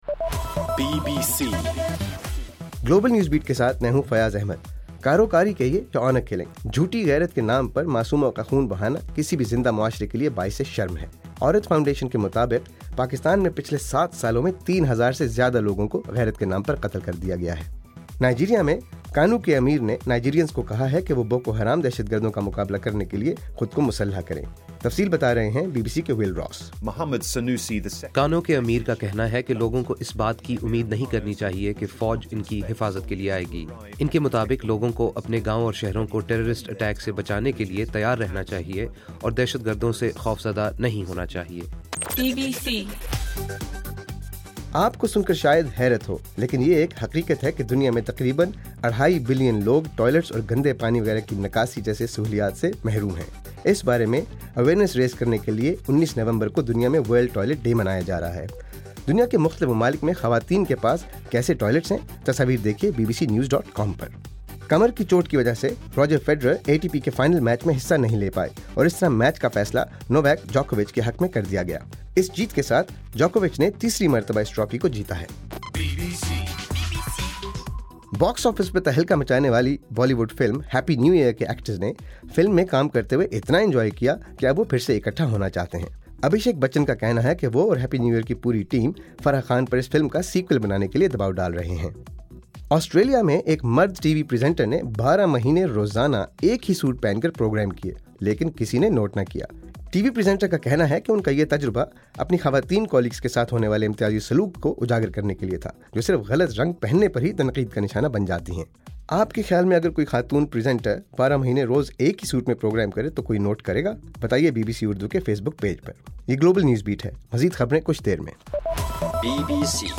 نومبر 17: رات 8 بجے کا گلوبل نیوز بیٹ بُلیٹن